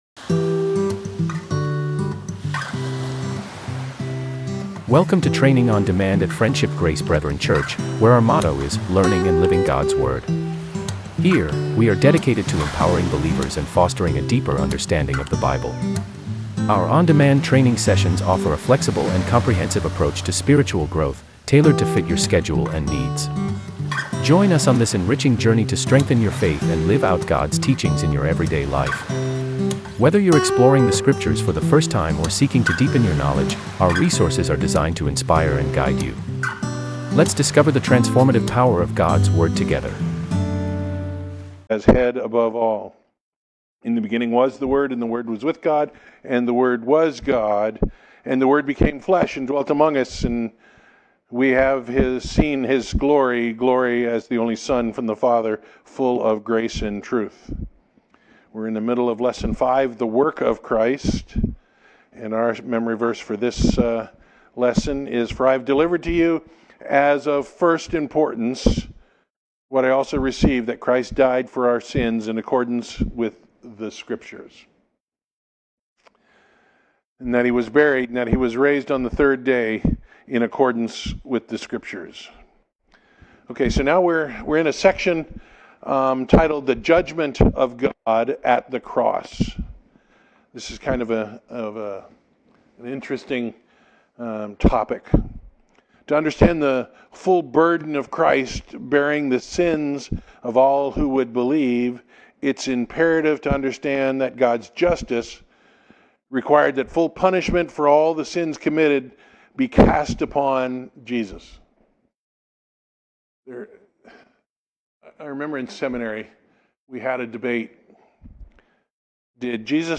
Series: Fundamentals of the Faith, Sunday School